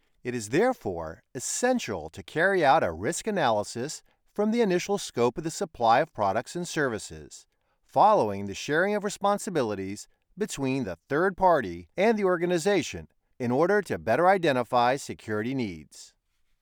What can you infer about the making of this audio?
• For voiceovers – a home studio with acoustic treatment, CM25 MkIII condenser microphone, Focusrite Scarlett Si2 interface, Adobe Audition Software.